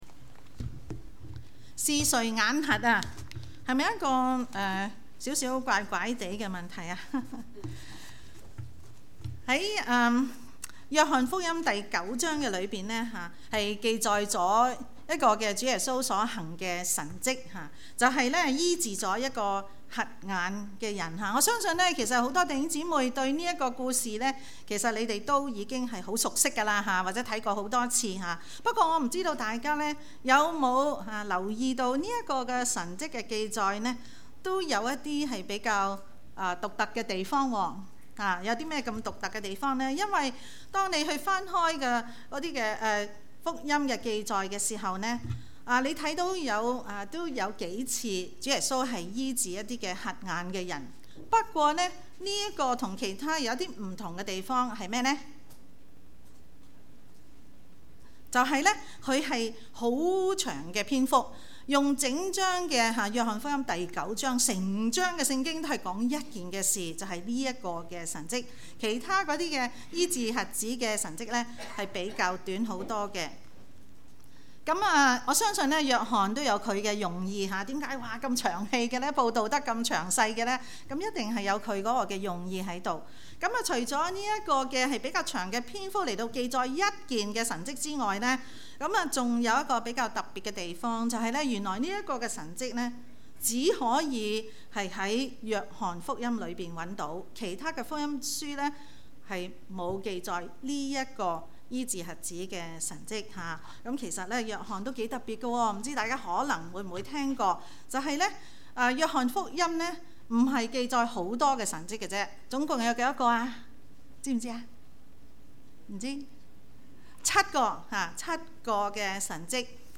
粵語講道 Bible Text